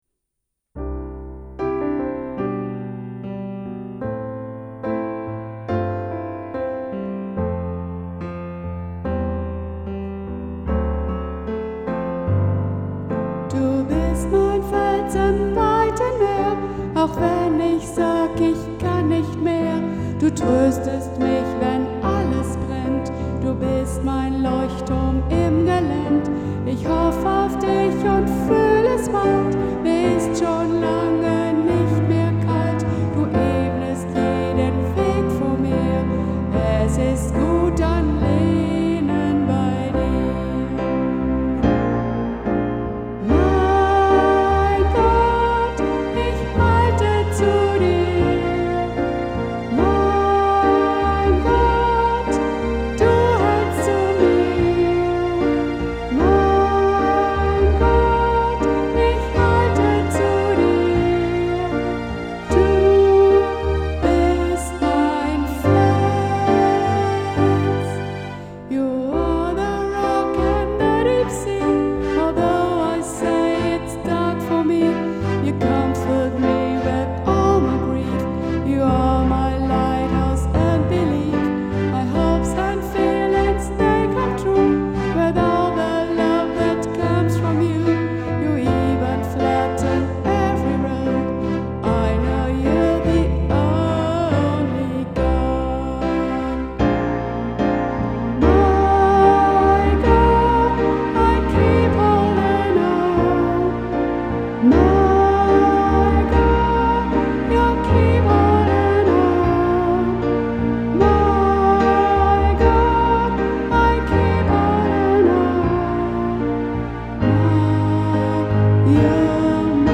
Musikalischer Gruß aus Hondelage